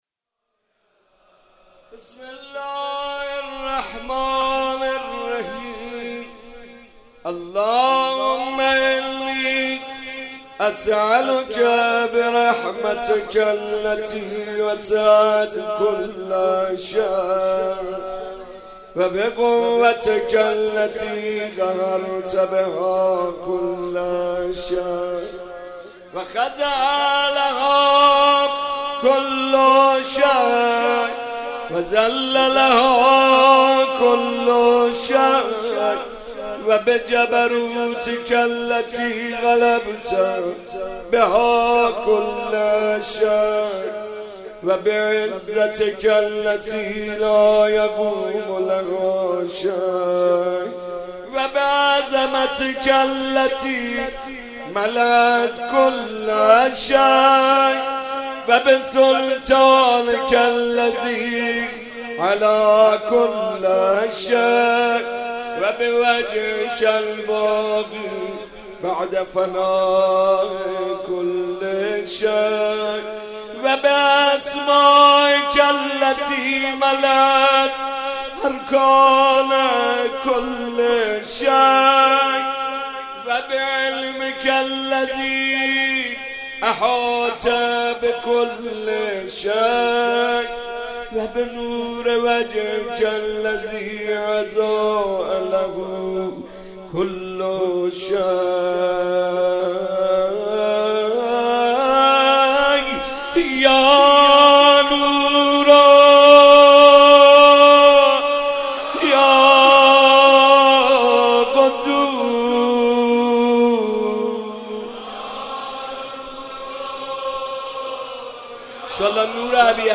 روضه محمود کریمی